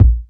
KICK_SPLIT_IN_HALF.wav